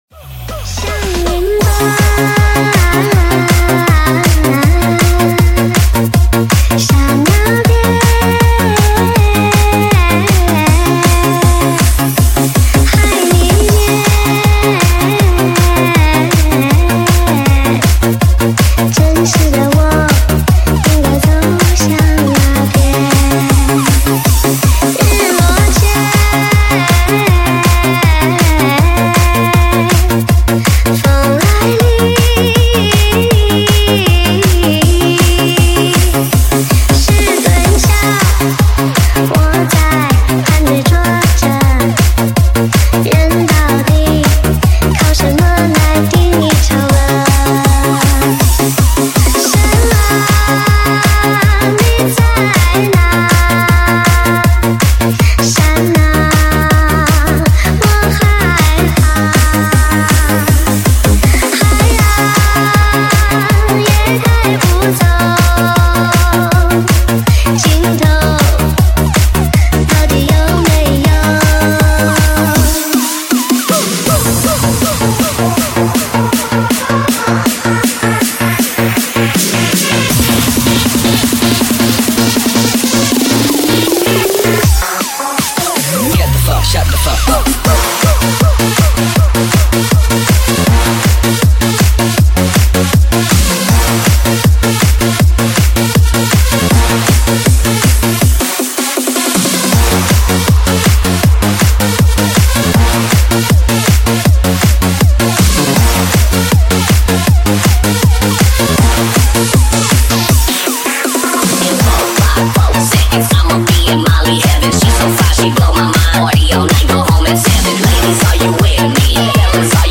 MANYAO ELECTRO REMIX